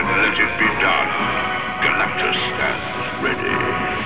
From the Fantastic Four animated series.